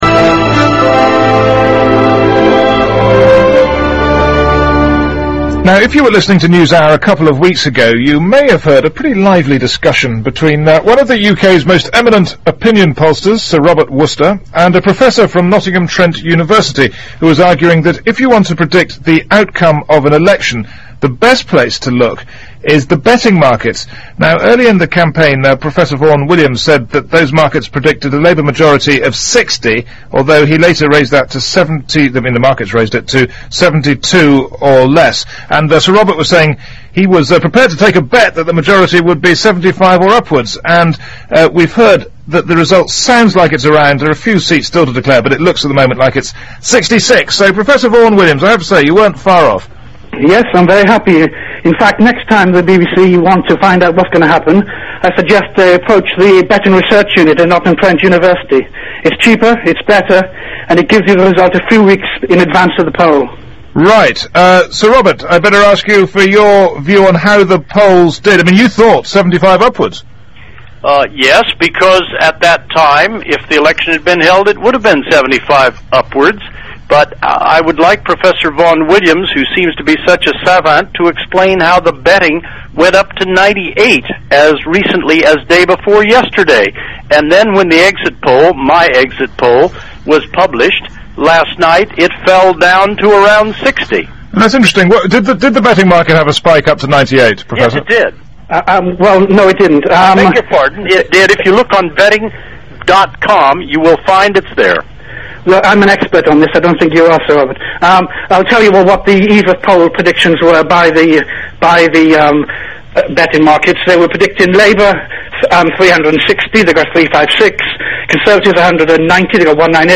BBC World Service Live Debate Prediction Markets
bbc-world-service-election-debate-may-8_01.mp3